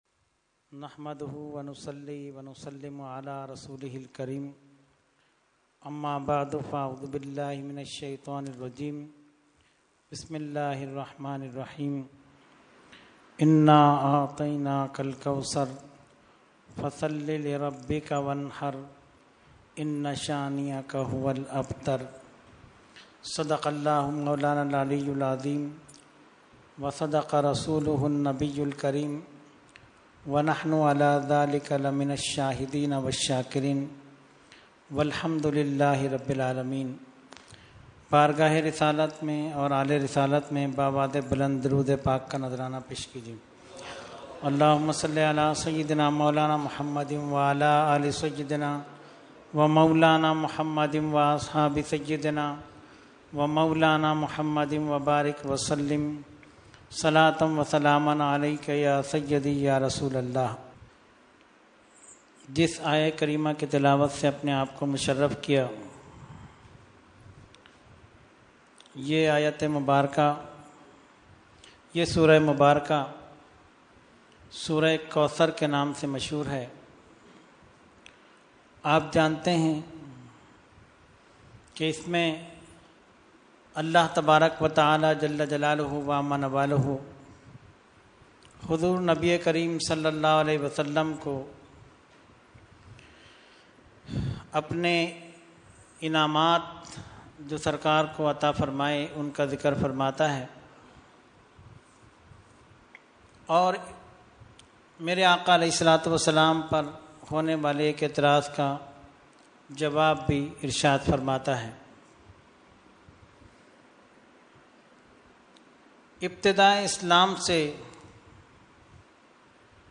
Weekly Tarbiyati Nashist held on 7/12/2014 at Dargah Alia Ashrafia Ashrafabad Firdous Colony Karachi.
Category : Speech | Language : UrduEvent : Weekly Tarbiyati Nashist